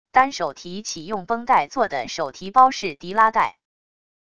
单手提起用绷带做的手提包式提拉带wav音频